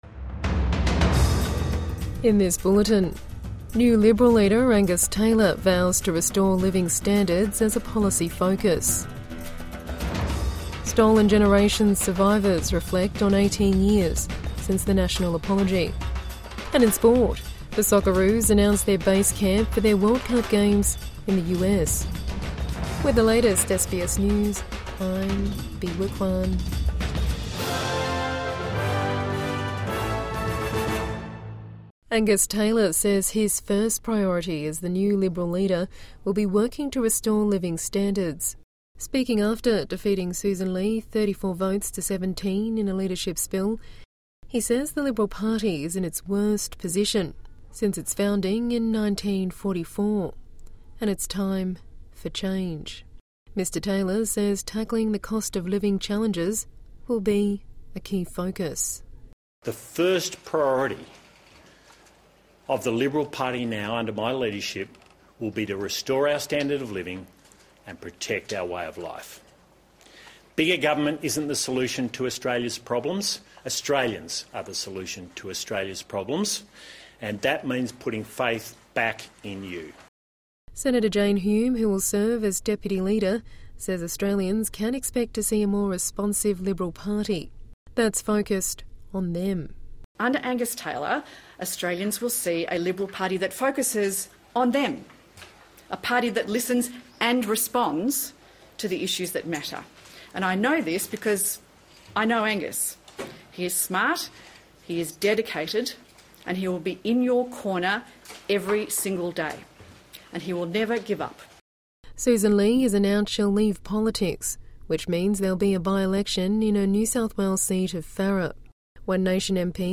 New Liberal leader Angus Taylor vows to restore living standards | Evening News Bulletin 13 February 2026